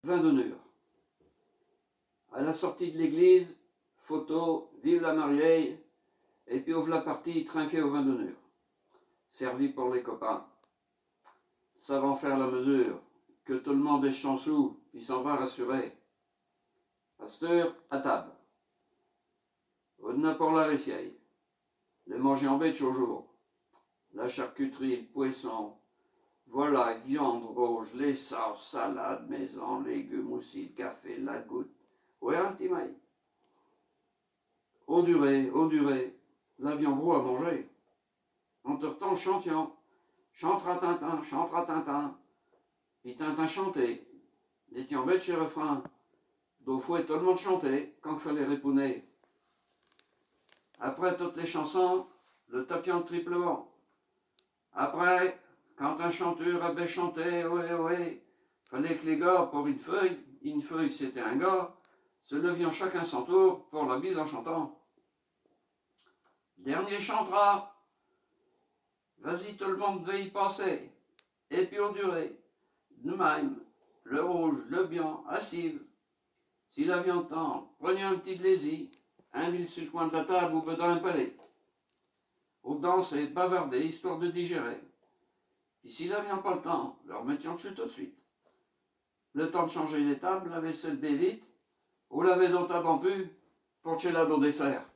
Langue Poitevin-Saintongeais
Genre poésie
Poésies en patois